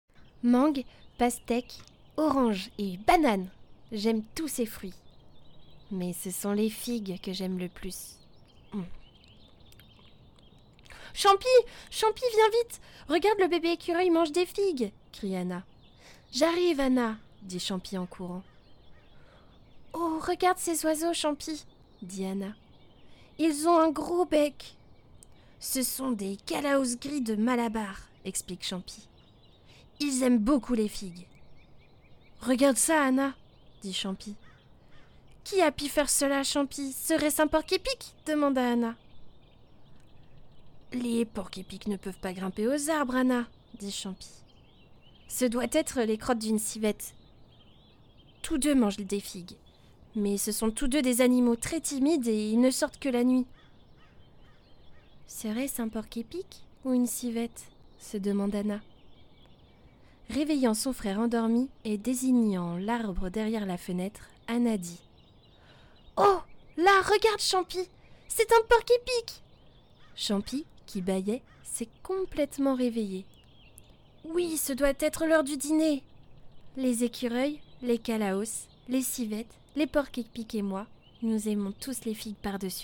Voix Histoire Enfant